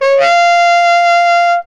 Index of /90_sSampleCDs/Roland L-CD702/VOL-2/SAX_Baritone Sax/SAX_Baritone FX